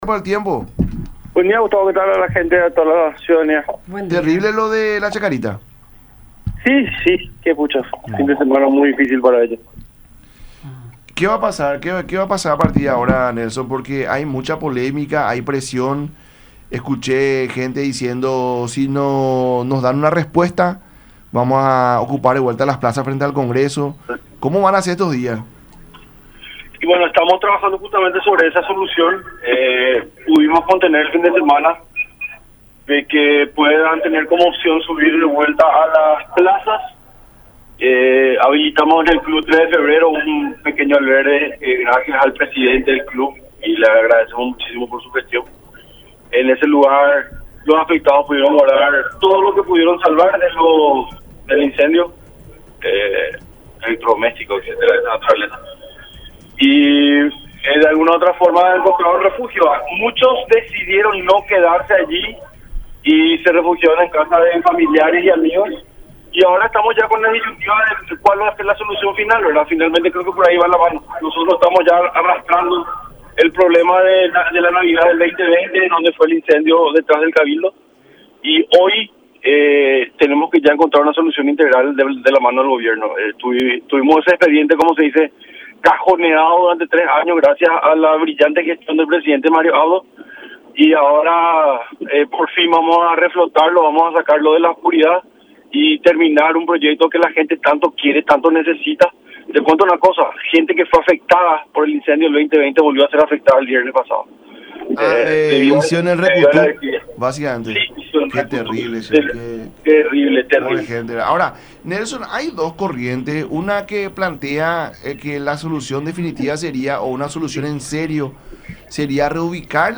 “Conseguimos un albergue, en ese lugar pudieron llevar todo lo que salvaron, de alguna u otra manera encontraron refugio, muchos decidieron no quedarse allí”, agregó en el programa “La Mañana De Unión” por radio La Unión y Unión Tv.